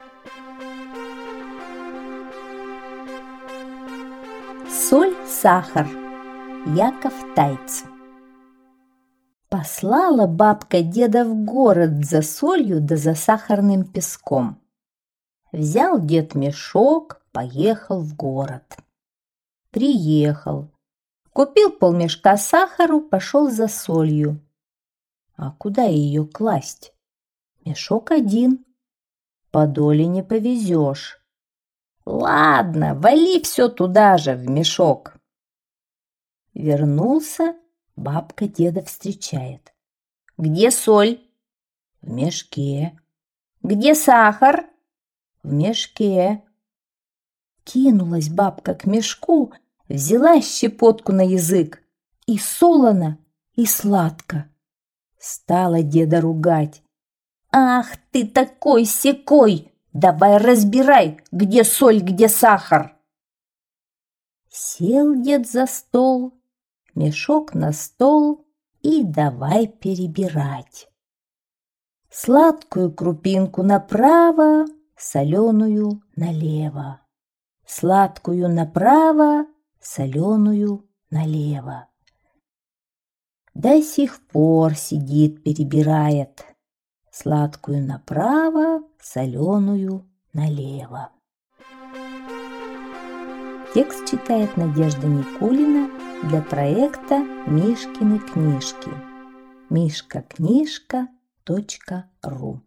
Соль-сахар - Тайц - слушать сказку онлайн